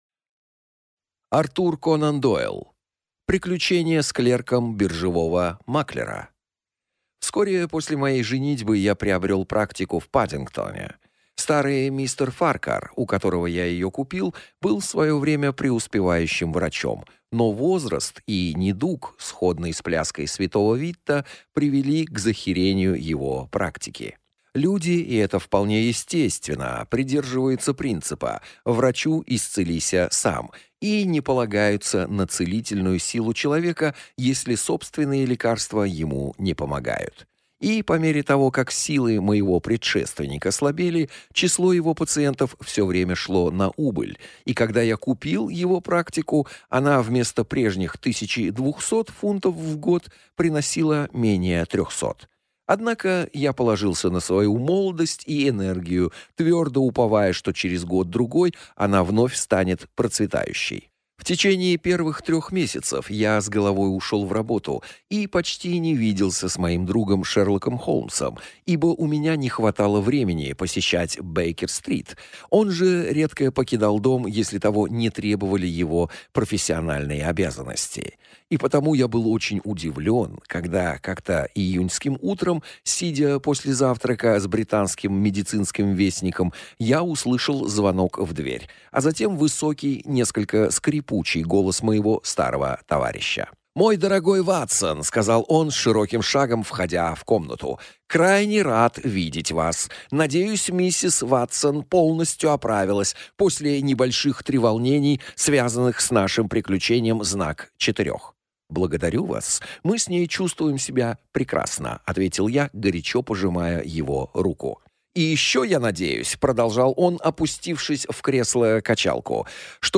Аудиокнига Приключение с клерком биржевого маклера | Библиотека аудиокниг